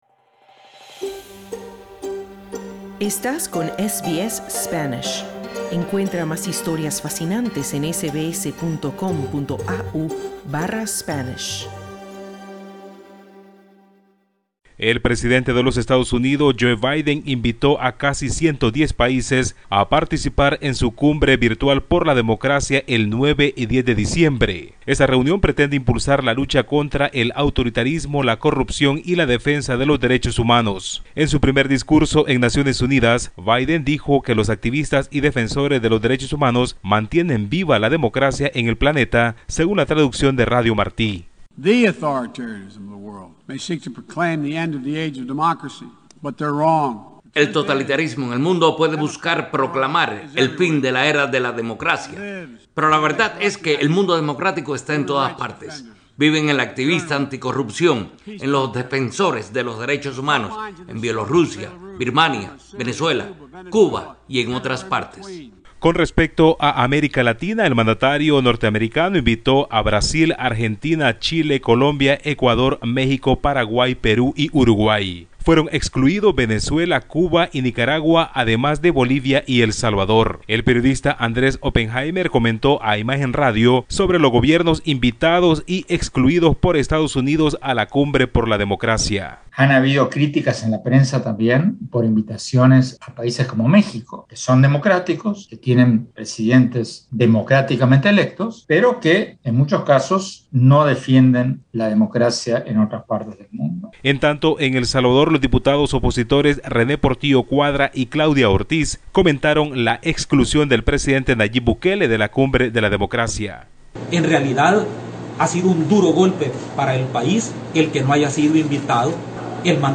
El presidente de Estados Unidos, Joe Biden, invitó a más de 100 países a una cumbre virtual por la democracia que se celebrará el próximo 9 y 10 de diciembre pero el mandatario excluyó a China, Rusia y cinco países latinoamericanos. Escucha el informe del corresponsal de SBS Spanish en Latinoamérica